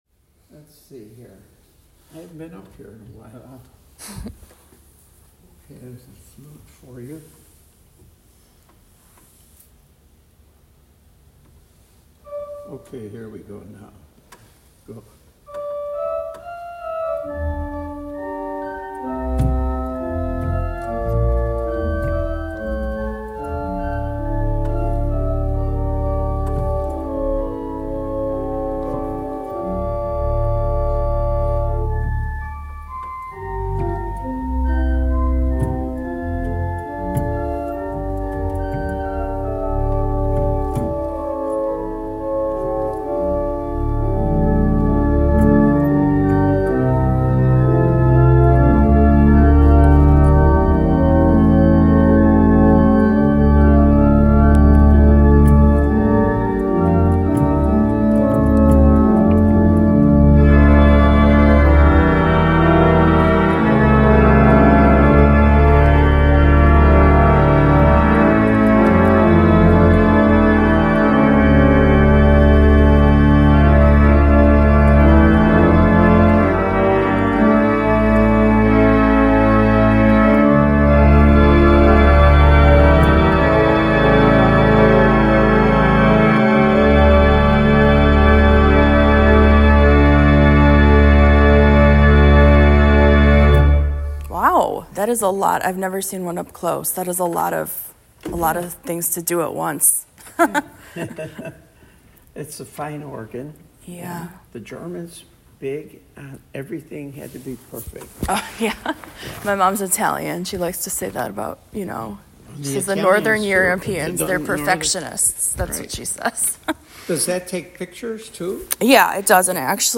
Organ playing